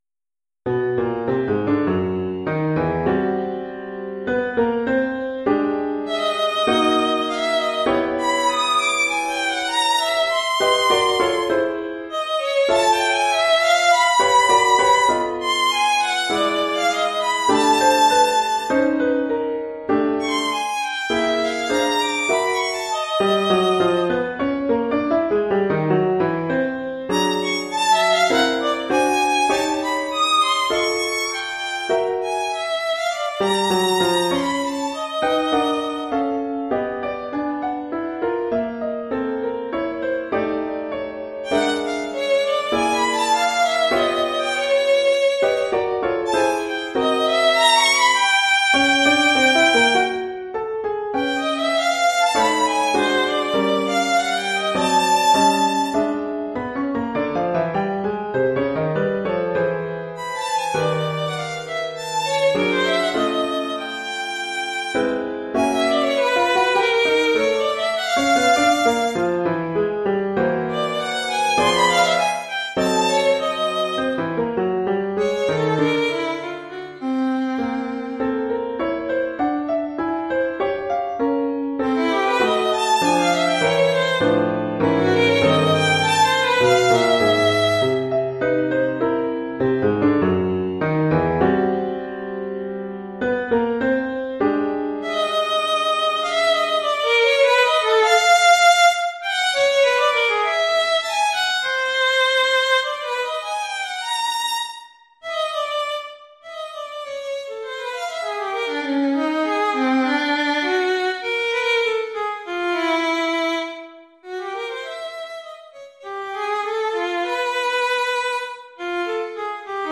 Oeuvre pour violon et piano..